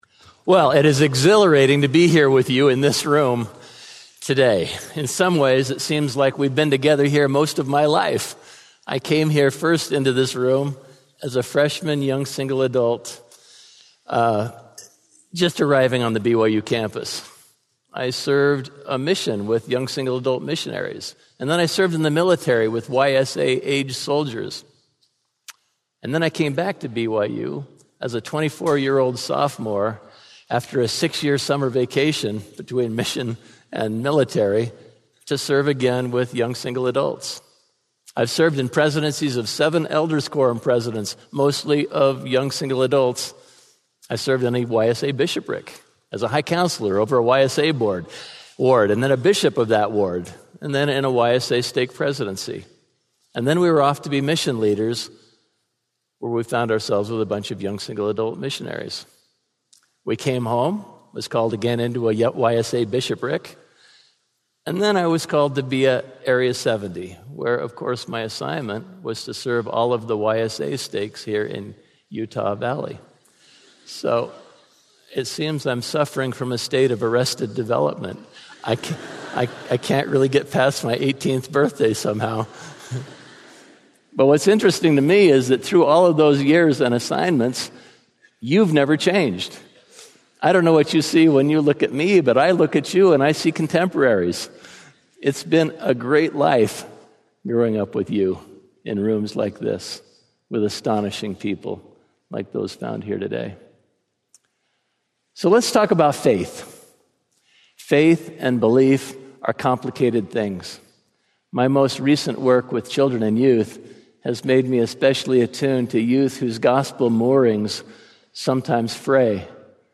Audio recording of Flashes of Light by Steven J. Lund